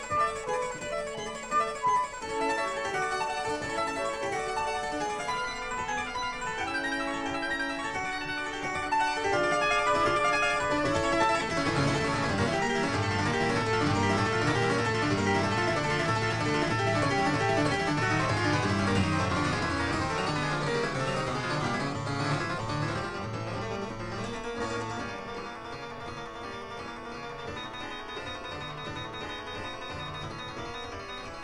flute
violin
harpsichord
1960 stereo recording made by